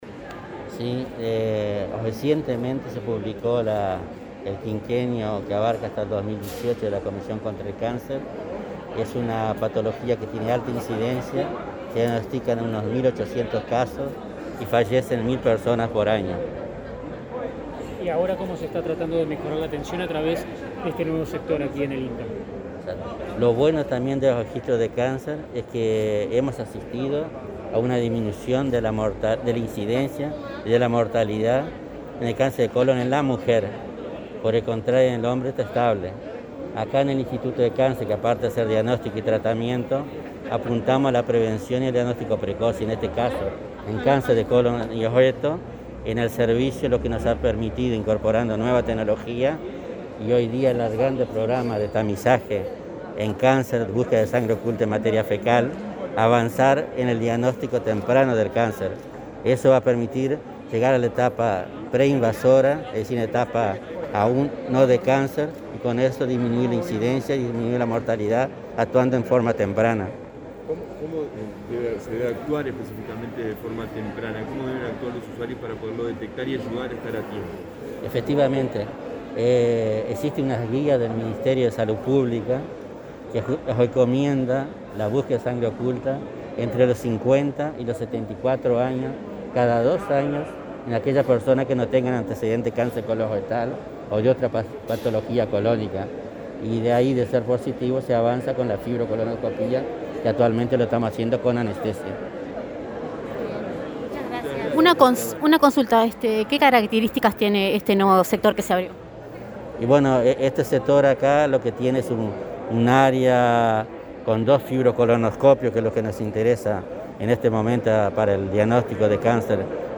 Declaraciones a la prensa del director del Instituto Nacional del Cáncer